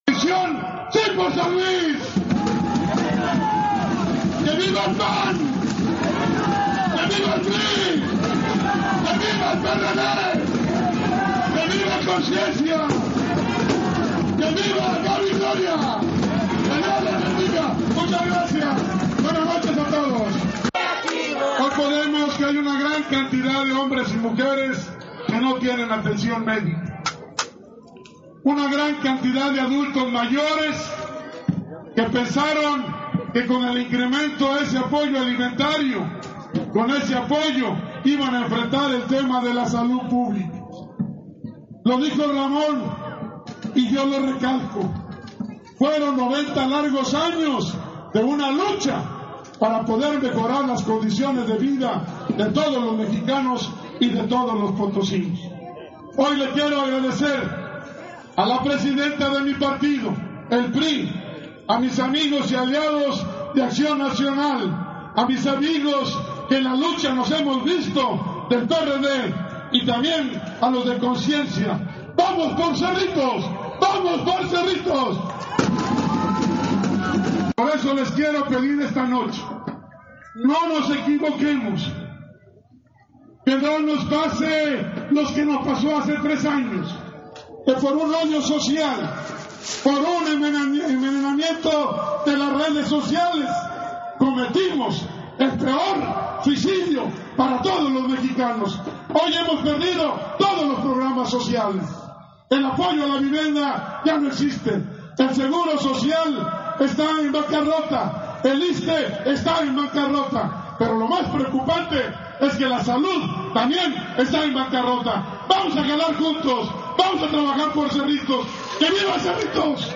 Mitin-Coalicion-Barrio-Guadalupe.mp3